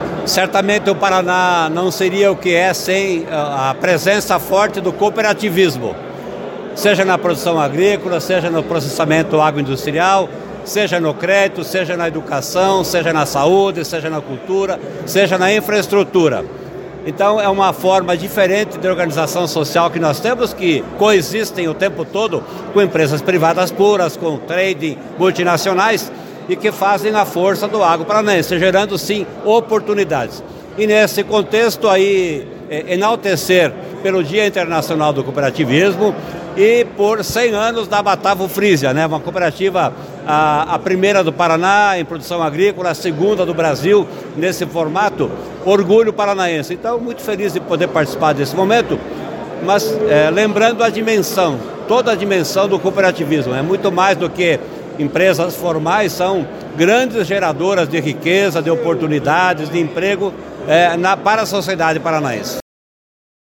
Sonora do secretário da Fazenda, Norberto Ortigara, sobre o Ano Internacional do Cooperativismo e os 100 anos da Cooperativa Frísia